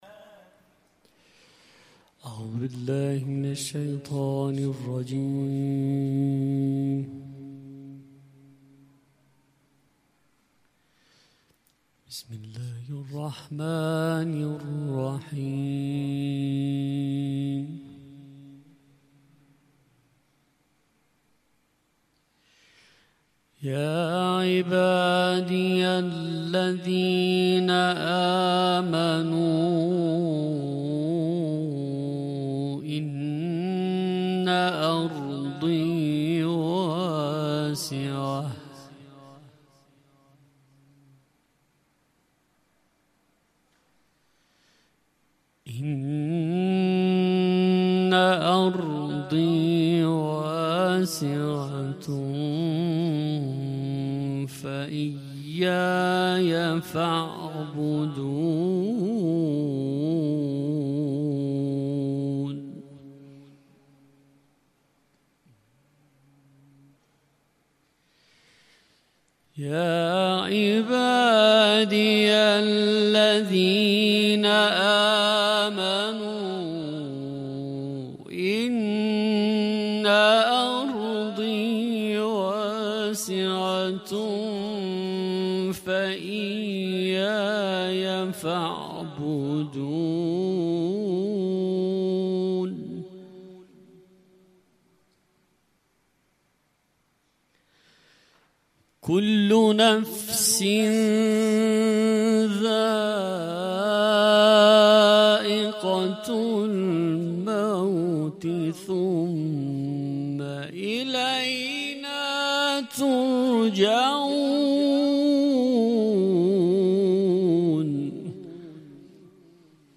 تلاوت قرآن کریم